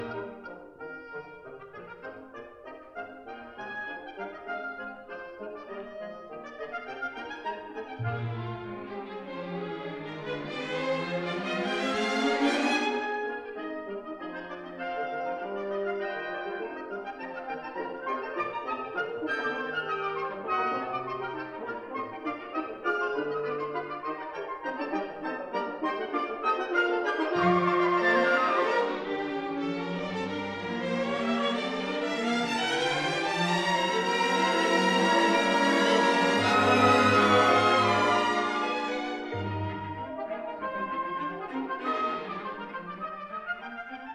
conductor
No. 1 Studio, Abbey Road, London